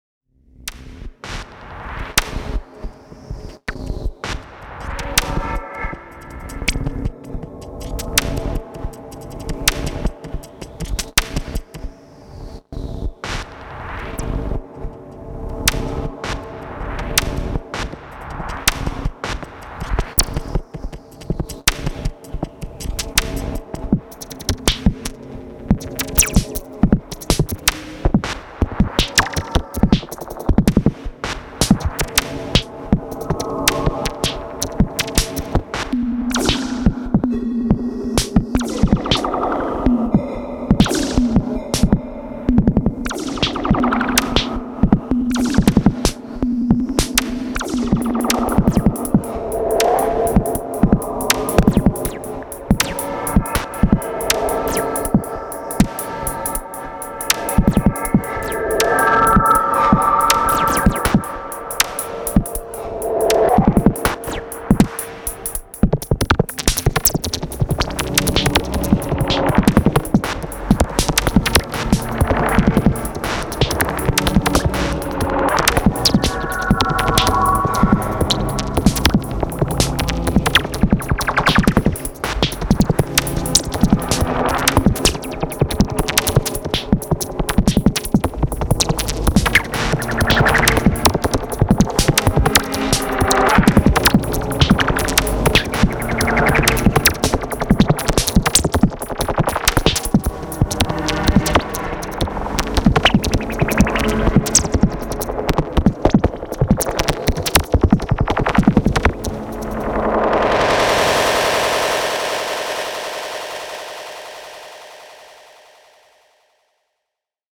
Rytm